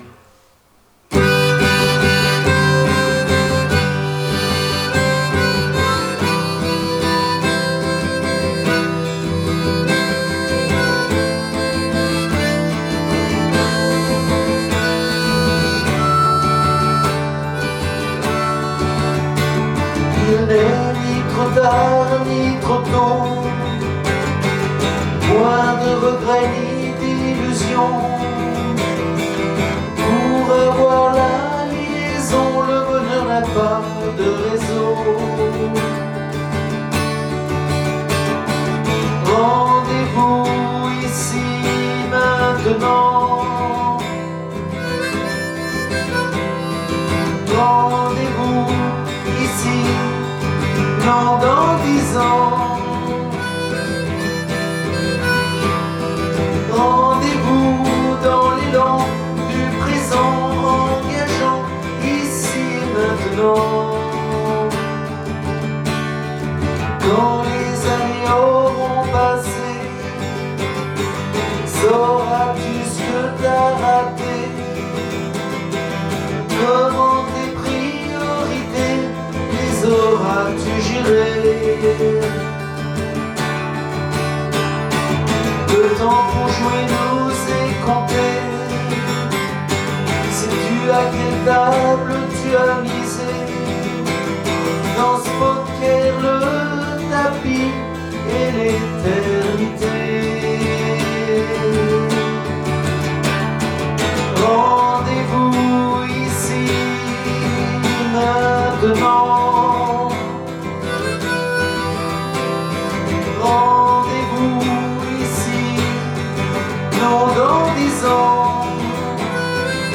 De retour à la civilisation, une valse viennoise en palais de lumière